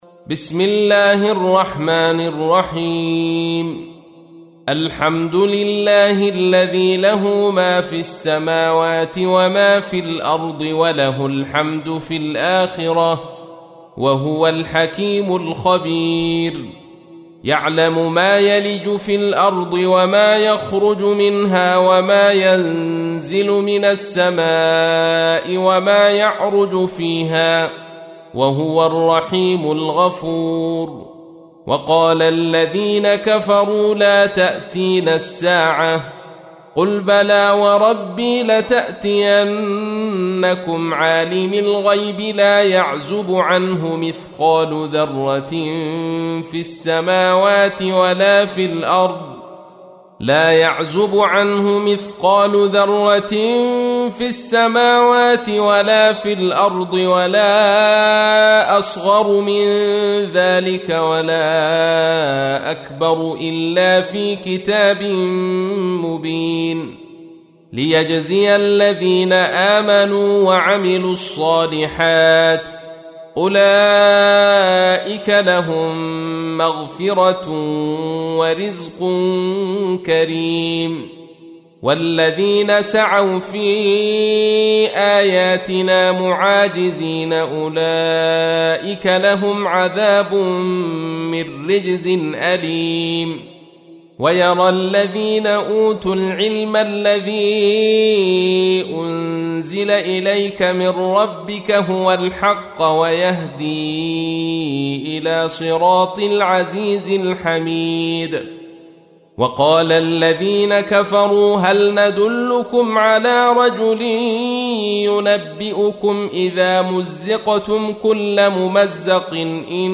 تحميل : 34. سورة سبأ / القارئ عبد الرشيد صوفي / القرآن الكريم / موقع يا حسين